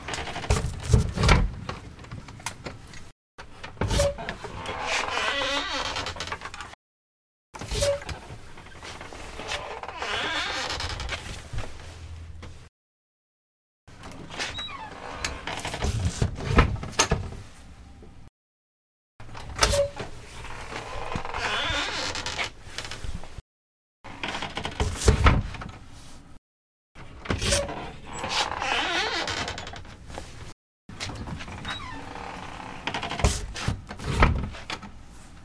描述：门木多节地下室地窖与闩锁打开关闭不均匀推关闭好grit.flac
Tag: 开放 锁存器 接近 地下室 木材 不均匀的 地窖 关闭 粗糙